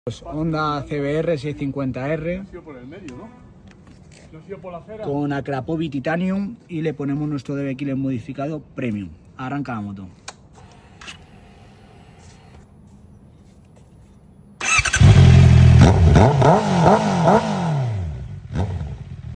🔊 Un rugido más agresivo 💥 Petardeos de escándalo 😎 Y una CBR que ahora suena tan bien como corre.
👇 ⸻ 💎 Today we had a Honda CBR650R with an Akrapovic Titanium exhaust.
🔊 A more aggressive roar 💥 Wild pops & bangs 😎 And a CBR that now sounds as good as it rides.